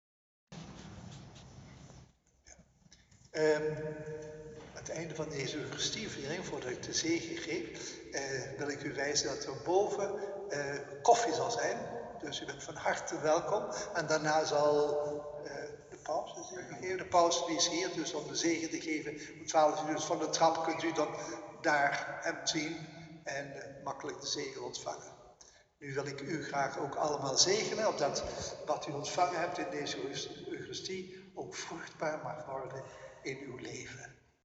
Viering 3 november 2019
slotwoord.mp3